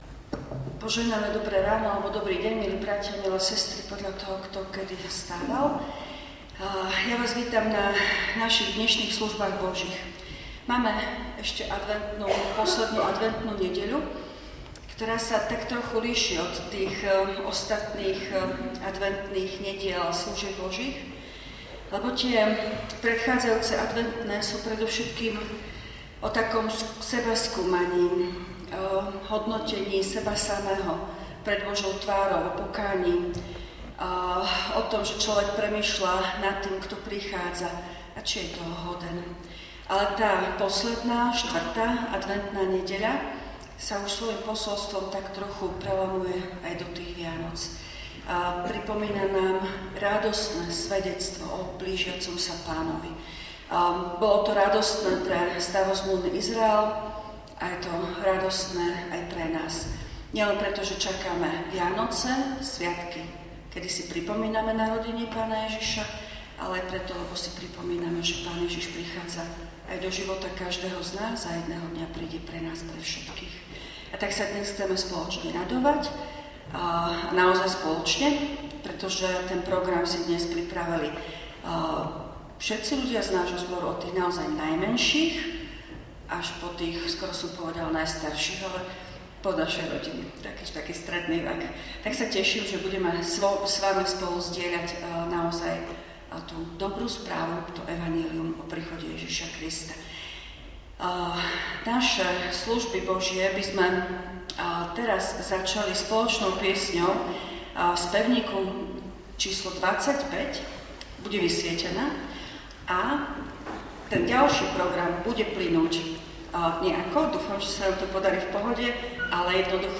V nasledovnom článku si môžete vypočuť zvukový záznam zo služieb Božích – 4. adventná nedeľa_ vianočné rodinné služby Božie.